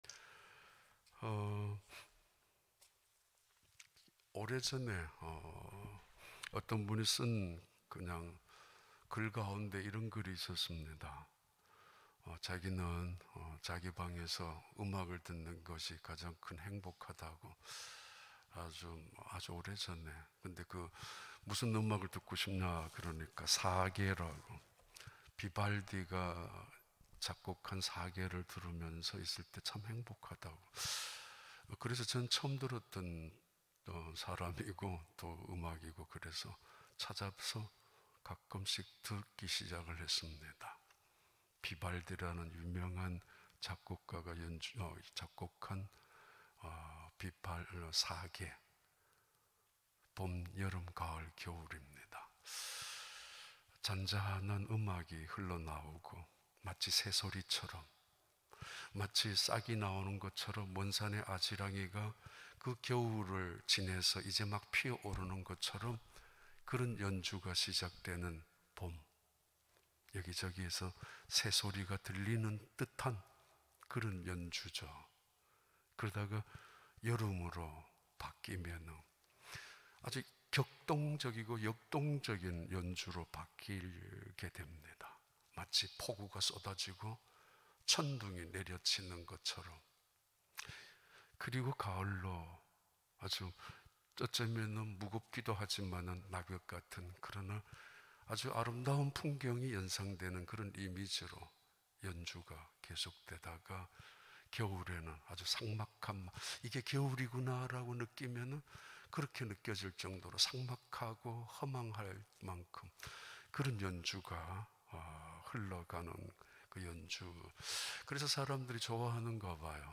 2020 연말 특별새벽기도 10